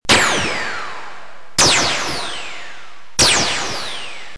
Photon shot with ricochets 2
Tags: Photon Sounds Photon Sound Photon clips Sci-fi Sound effects